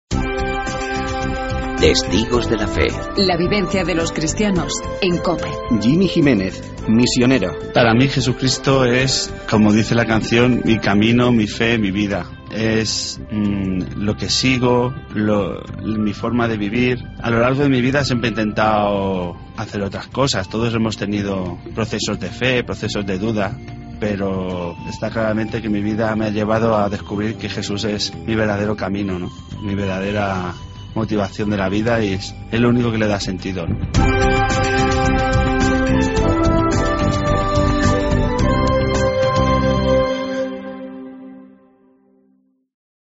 misionero.